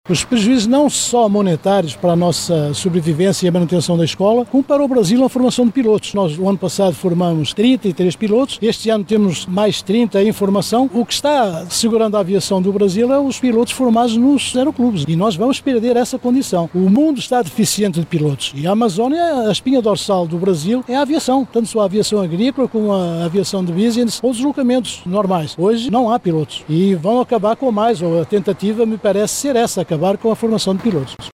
Em uma entrevista coletiva